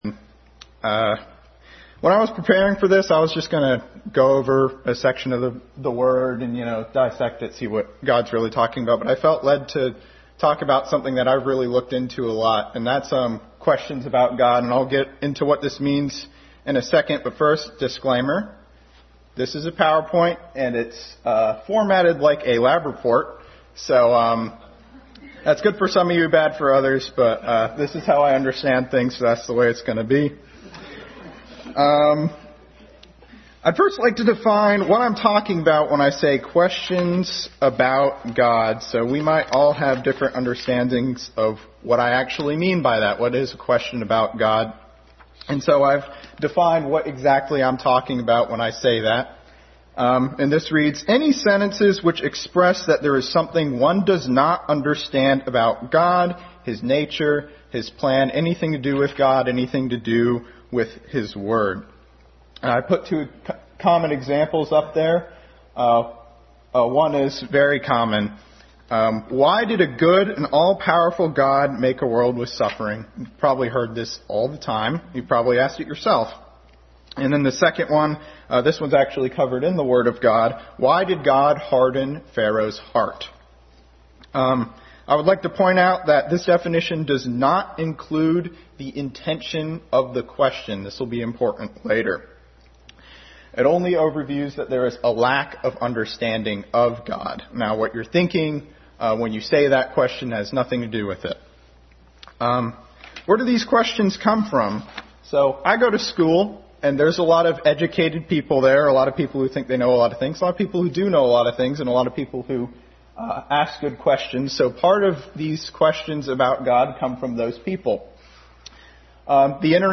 Family Bible Hour message.
Service Type: Family Bible Hour